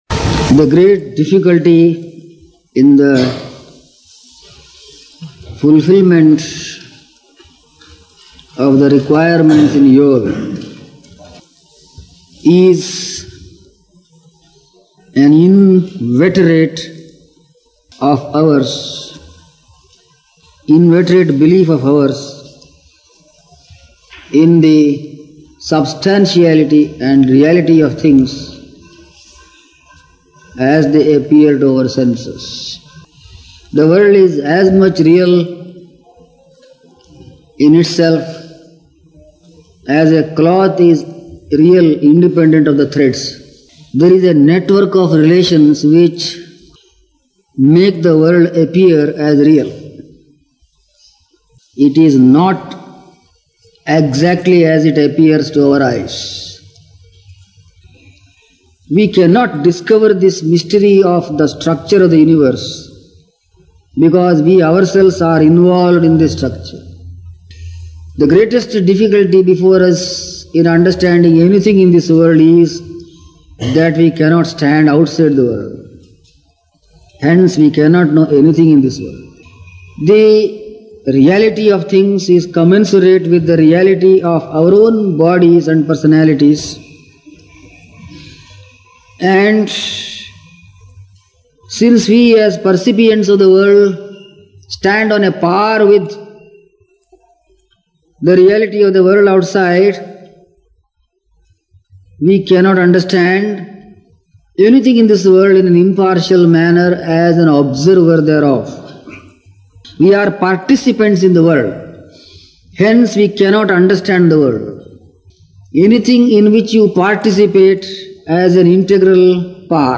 Lectures on epistemology, the philosophical theory of knowledge - Chapter 18.